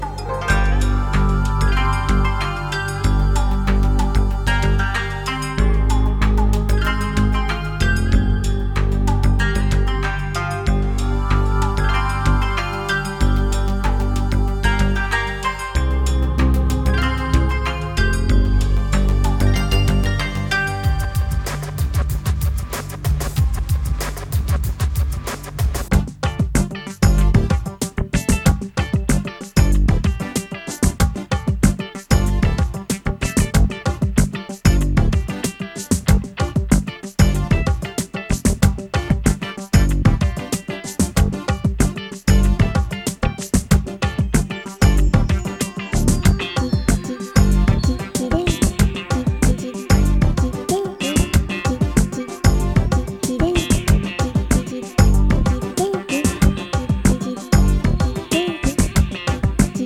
Ambient , Downtempo , Electronic